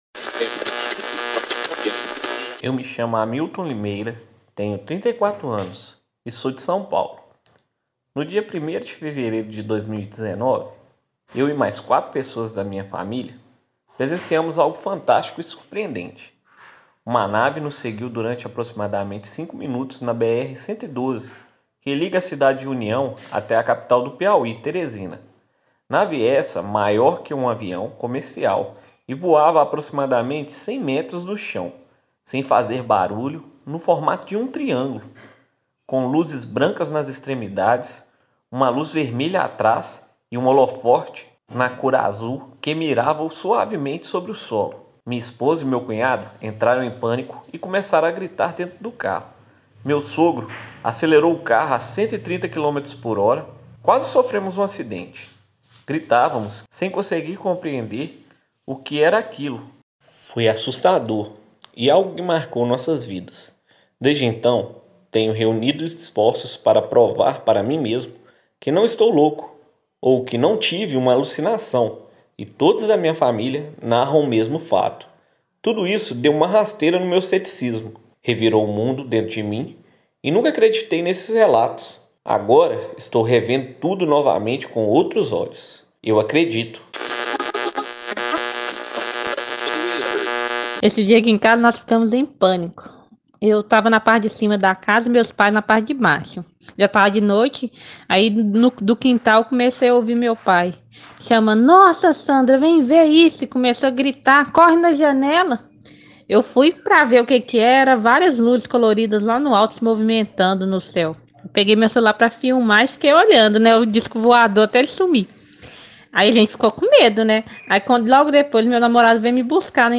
Na voz de anônimos, ouça o relato de três experiências com seres não identificados.